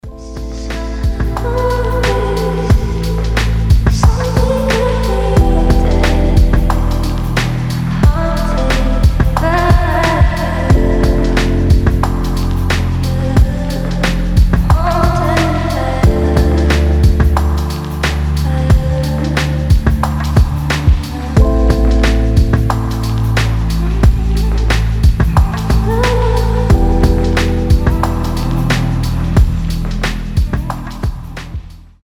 спокойные
chillout
Downtempo
медленные
расслабляющие
Ambient